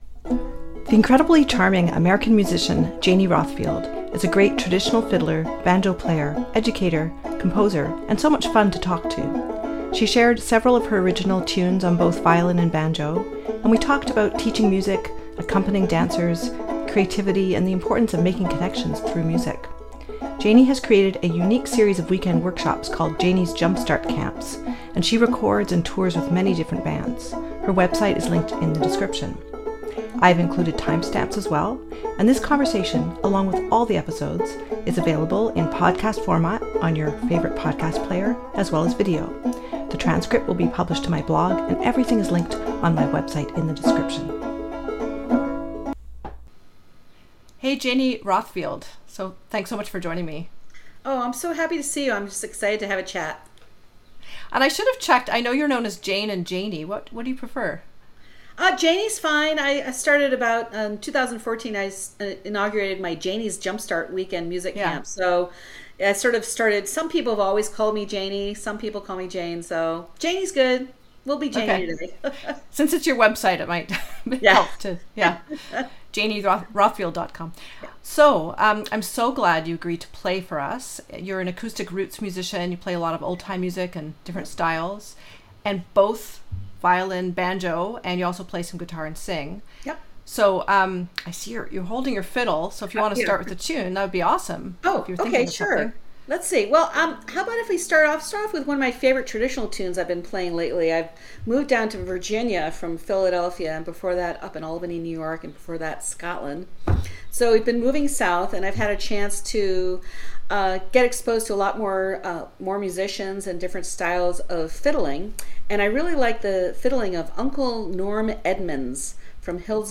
This episode features quite a bit of music on both fiddle and banjo. The conversation delved into the best ways to teach music, learn tunes, play with ease, accompany dancers and most importantly find connection with others through music!